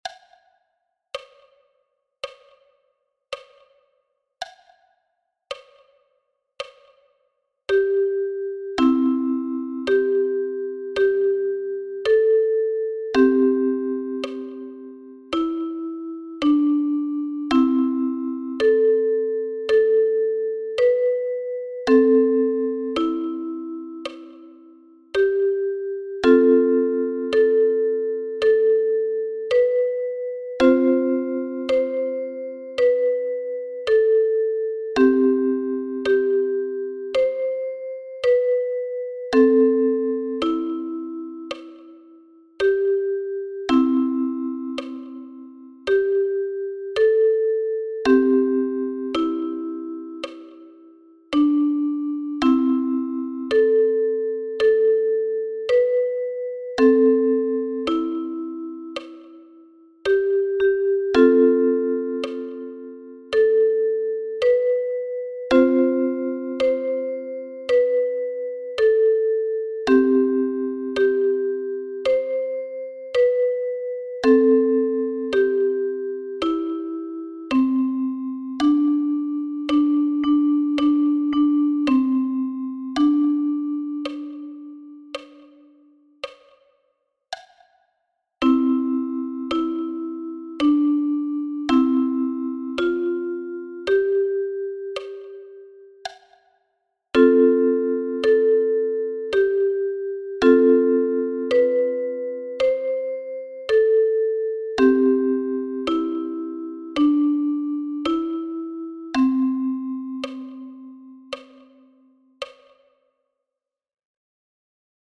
Der Song ist in einem langsamen Tempo aufgenommen. Die Instrumentierung wurde so gewählt dass sich der Klang möglichst wenig mit dem des eigenen Instrumentes vermischt.
Ukulele